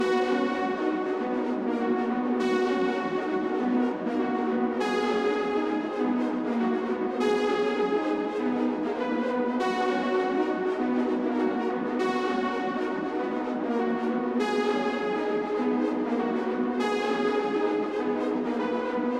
• Distant space texture Poly.wav
Distant_space_texture_Poly__nDE.wav